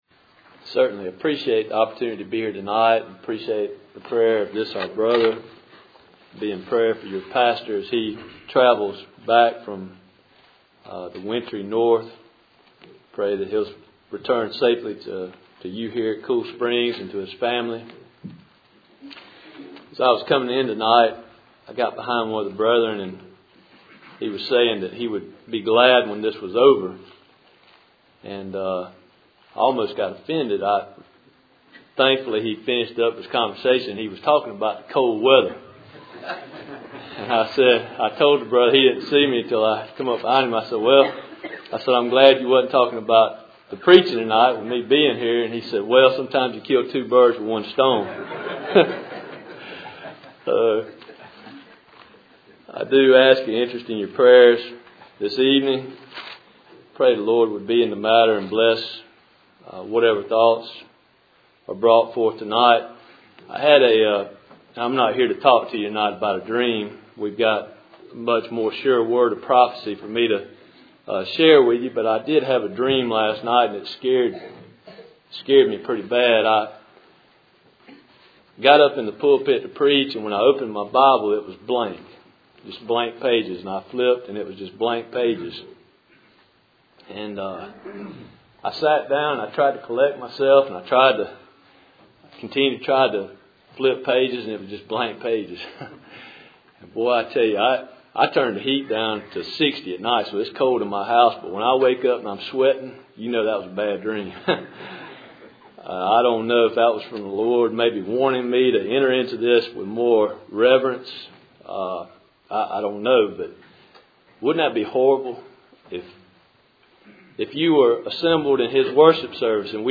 Cool Springs PBC Sunday Evening %todo_render% « Jonah